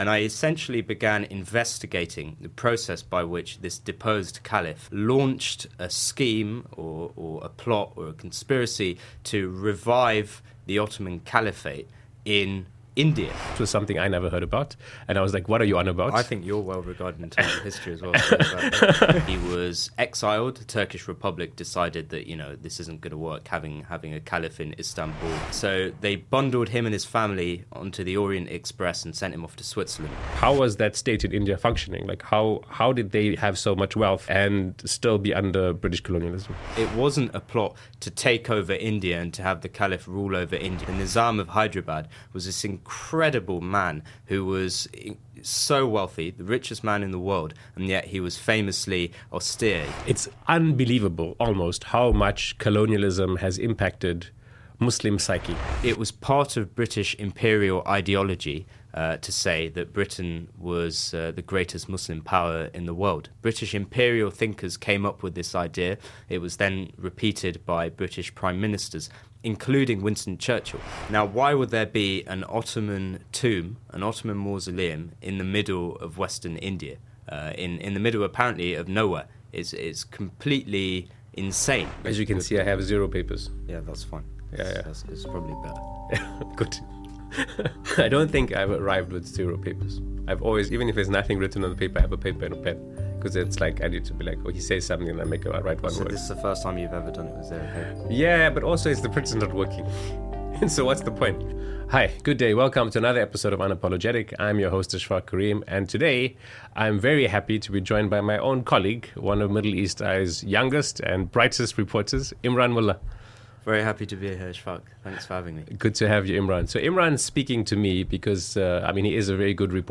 Intro & Soundbites